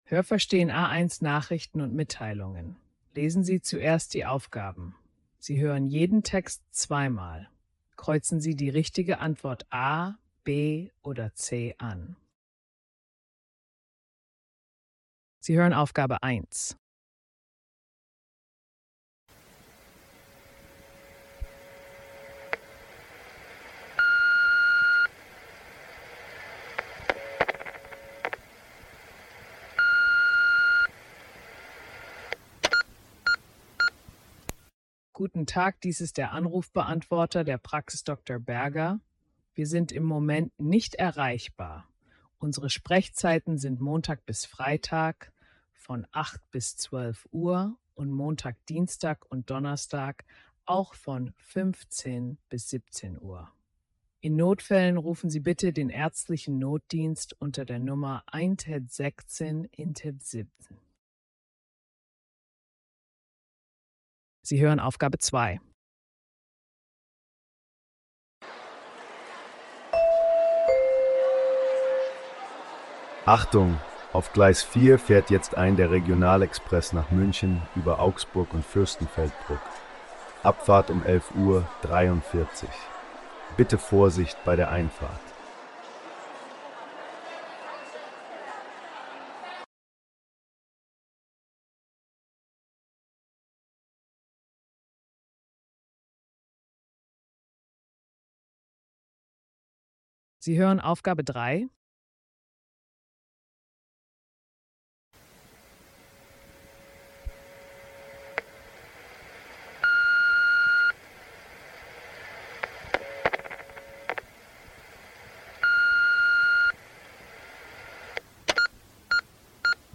Aufgabe 1:Diese Aufgabe ist typisch für viele A1-Prüfungen. Du hörst kurze Ansagen und Meldungen und musst die richtige Antwort auswählen (Detailverstehen).